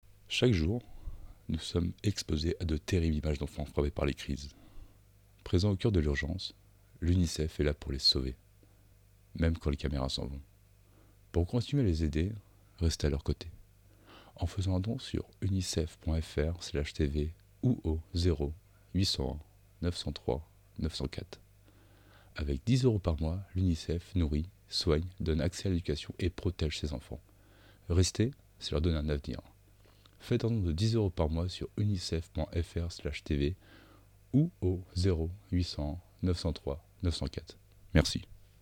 Voix off
Voix - Basse Baryton-basse